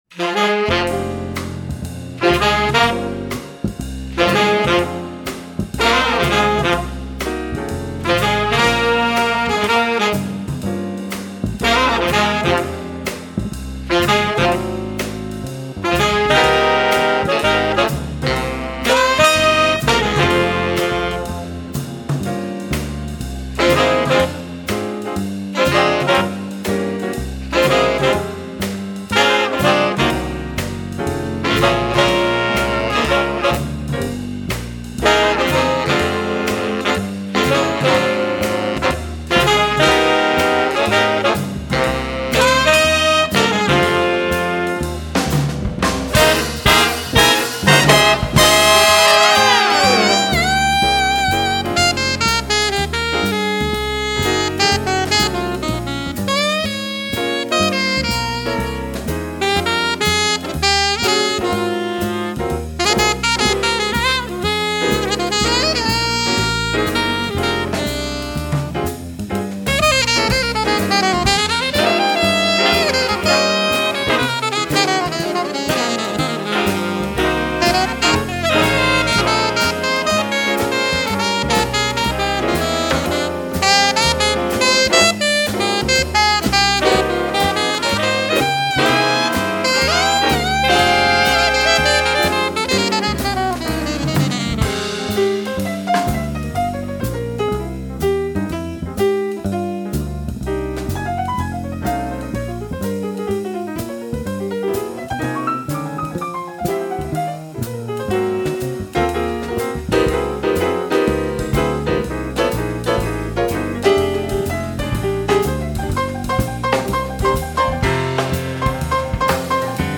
little big band
some of the better soloists in Hamburg